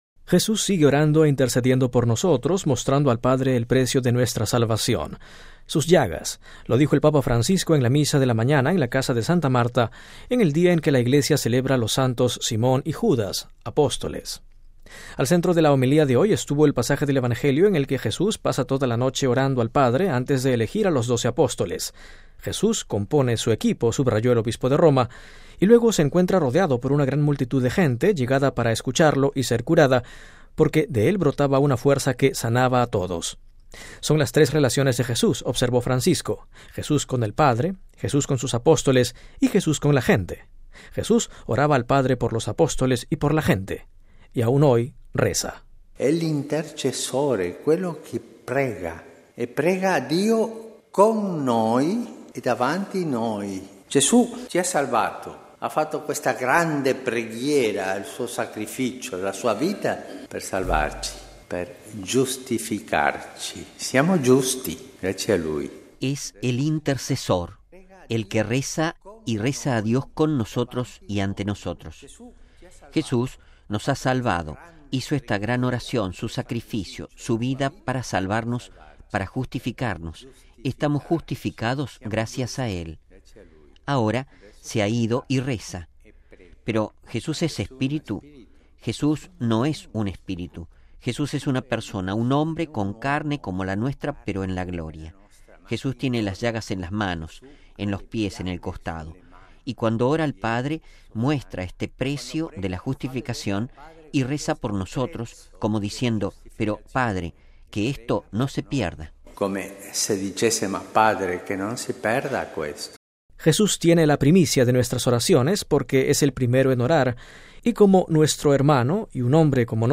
MP3 Jesús sigue orando e intercediendo por nosotros, mostrando al Padre el precio de nuestra salvación: sus llagas. Lo dijo el Papa Francisco en la misa de la mañana en la Casa de Santa Marta, en el día en que la Iglesia celebra los Santos Simón y Judas, Apóstoles.